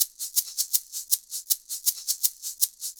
Shaker 06.wav